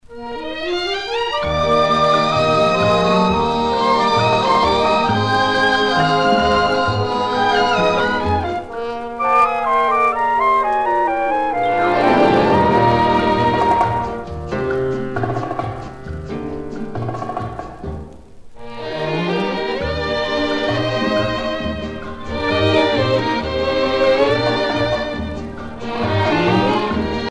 danse : rumba
Pièce musicale éditée